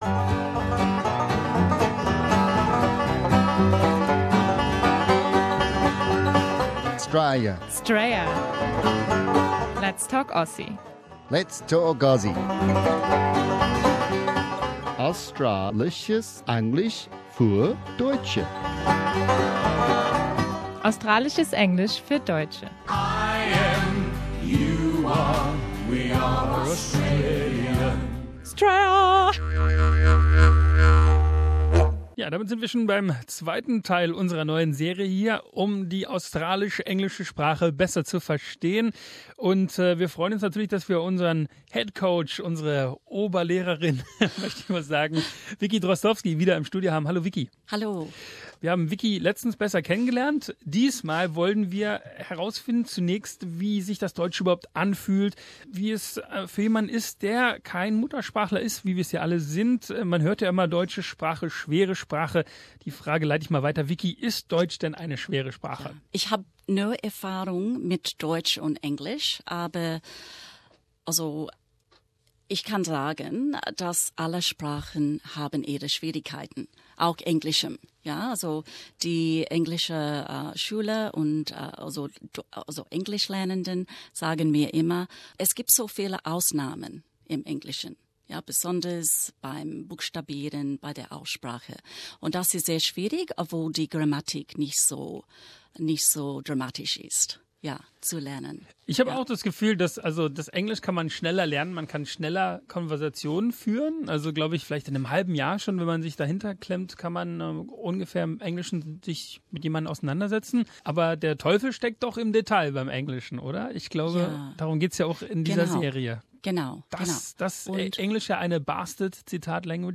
'Straya: Let's talk Aussie - Australisches Englisch für Deutsche ist eine wöchentliche Radioserie auf SBS, die den Fragen zum australischen Englisch nachgeht.